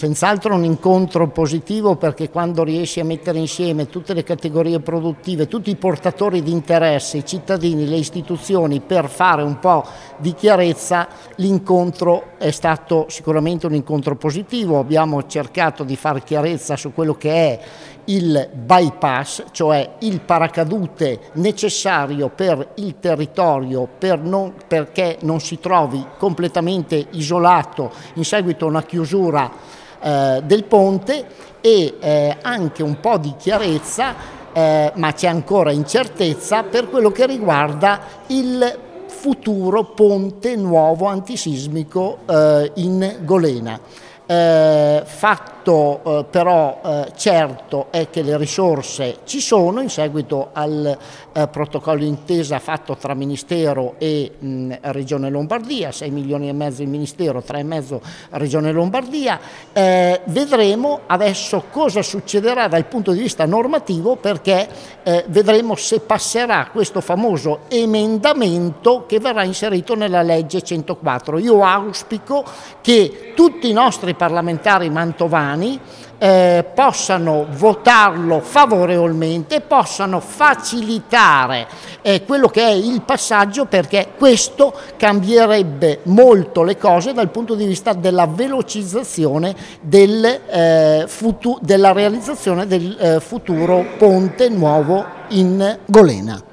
Ecco le dichiarazioni raccolte nella giornata dell’incontro:
Roberto Lasagna, sindaco di San Benedetto Po
Ponte-sindaco-roberto-lasagna.mp3